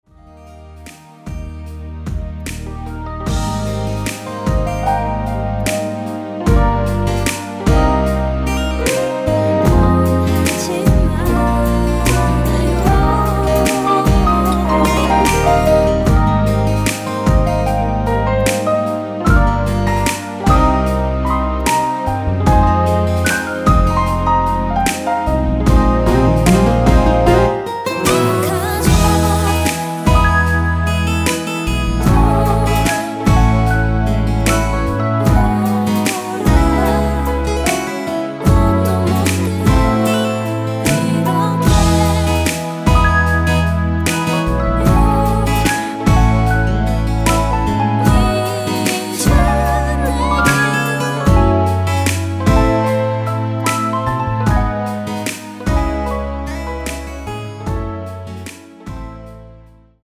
원키 코러스 포함된 MR입니다.(미리듣기 참조)
Eb
앞부분30초, 뒷부분30초씩 편집해서 올려 드리고 있습니다.
중간에 음이 끈어지고 다시 나오는 이유는